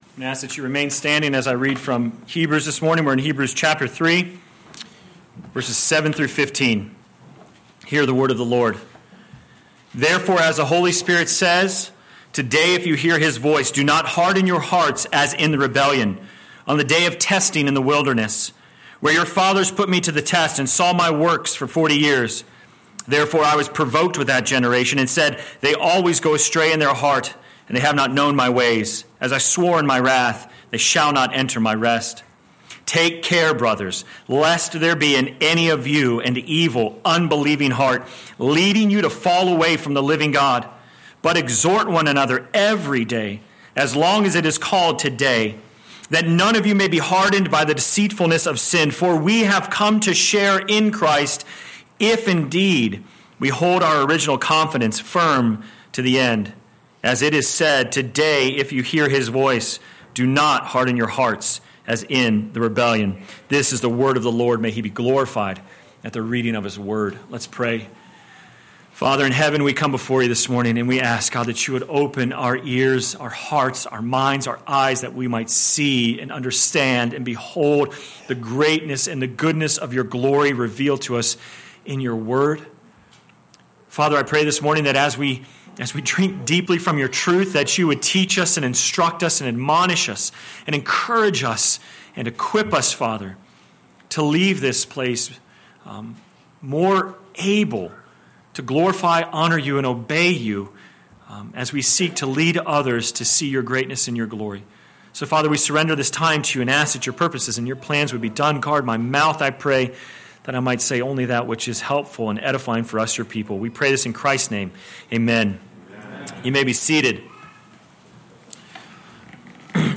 Sermon Text: Hebrews 3:7-15 First Reading: Psalm 95 Second Reading: Galatians 6:1-10